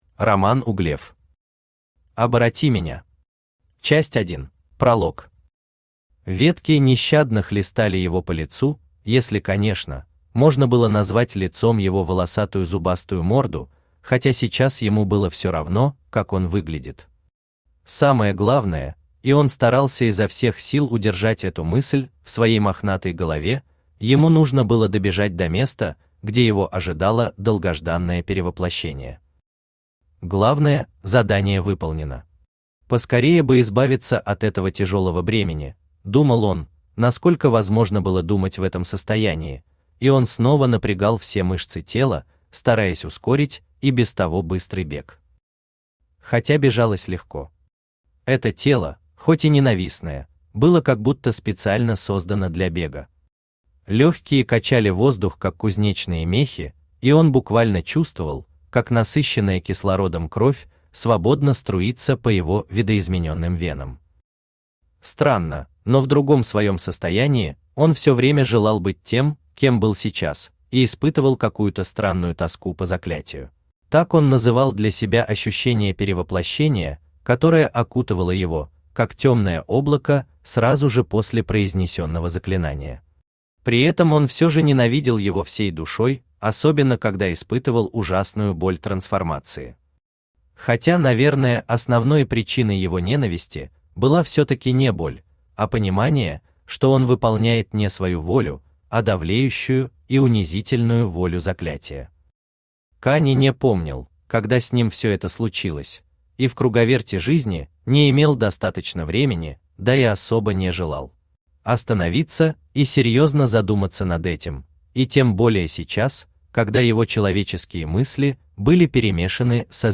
Часть 1 Автор Роман Романович Углев Читает аудиокнигу Авточтец ЛитРес.